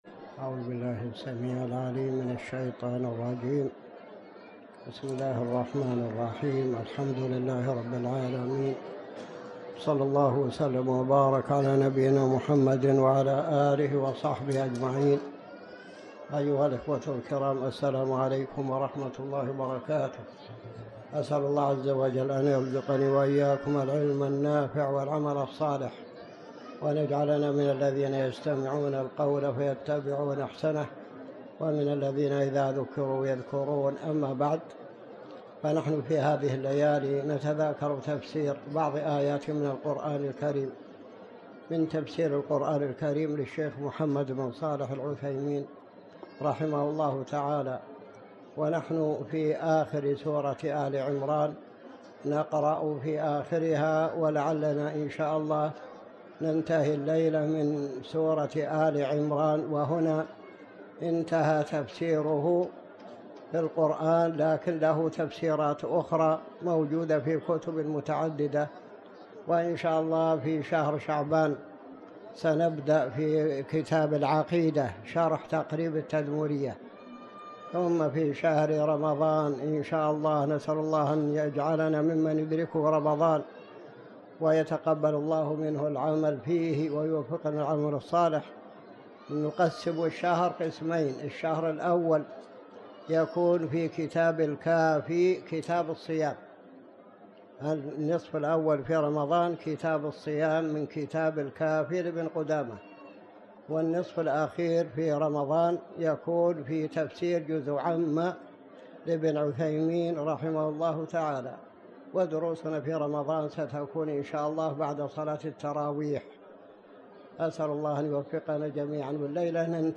تاريخ النشر ٢٧ رجب ١٤٤٠ هـ المكان: المسجد الحرام الشيخ